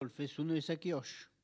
Localisation Bouin
Catégorie Locution